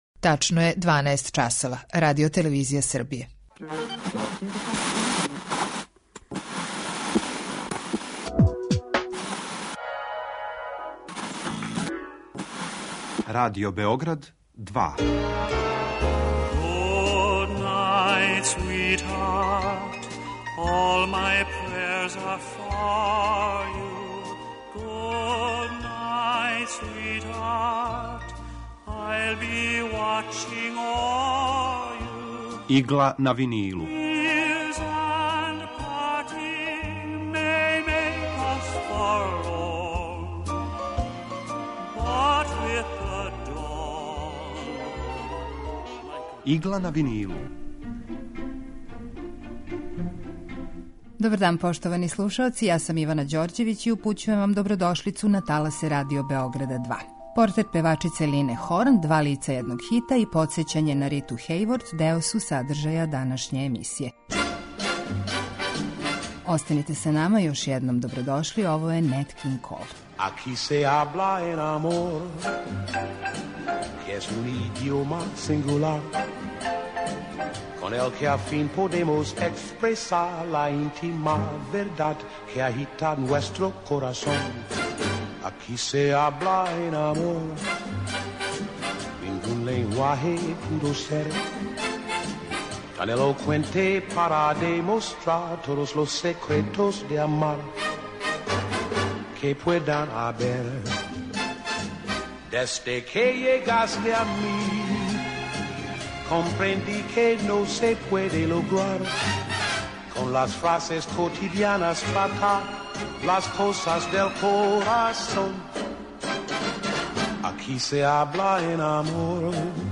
Емисија евергрин музике